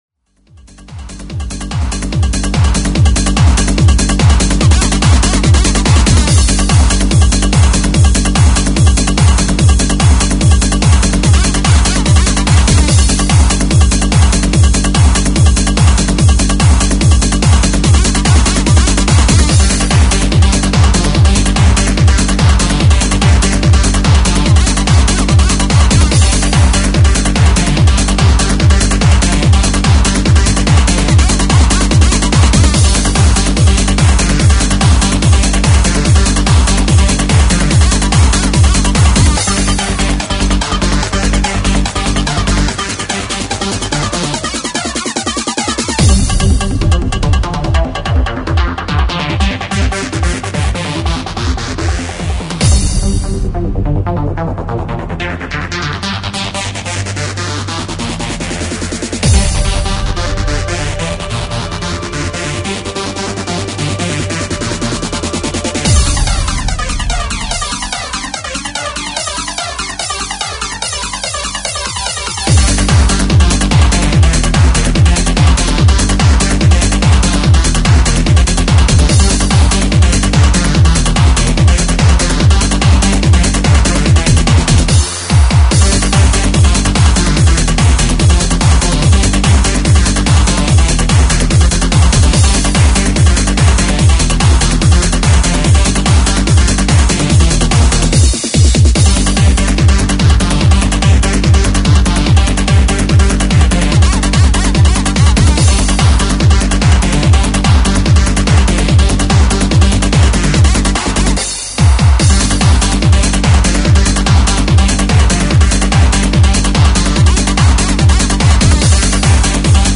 Hard Dance